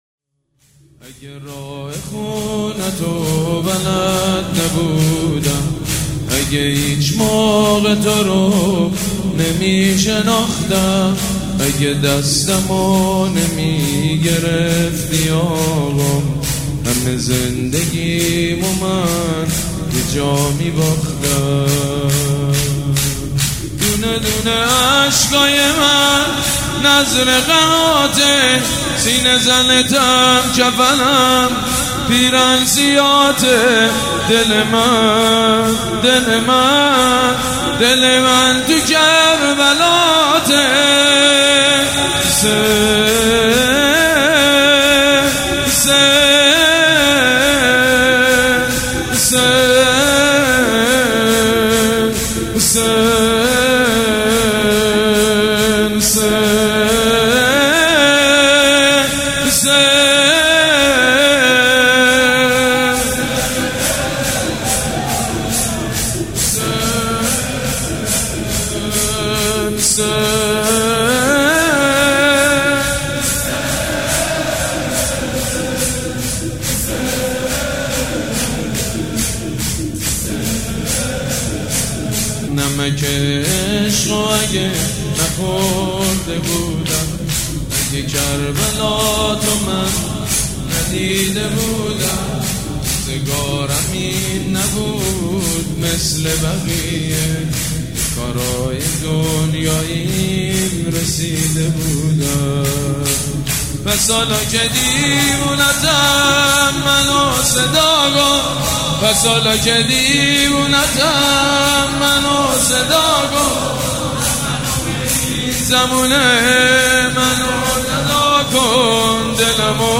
شب شهادت امام صادق علیه السلام
حسینیه ی ریحانه الحسین
شور
مداح
حاج سید مجید بنی فاطمه